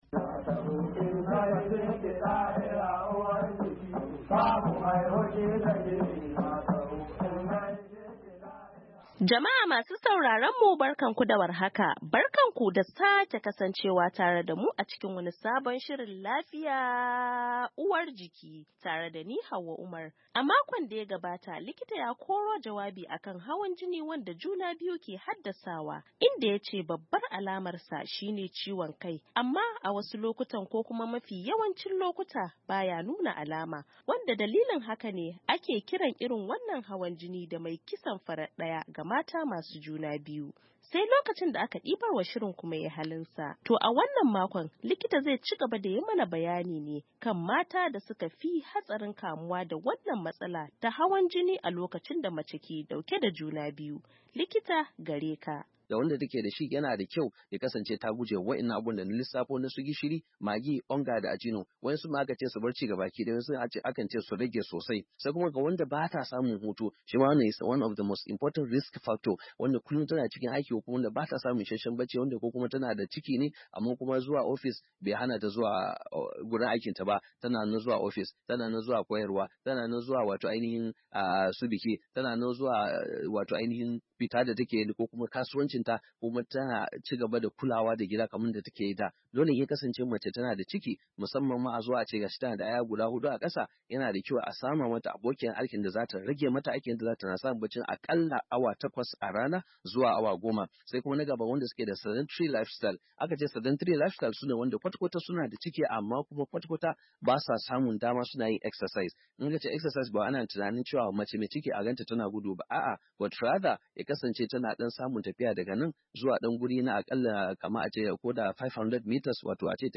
A shirin Lafiya na wannan makon mun ci gaba ne da tattaunawa da wani kwararren likiti a fannin haihuwa inda ya yi karin haske kan matan da suka fi hatsarin kamuwa da hawan jini wanda juna biyu ke haddasawa.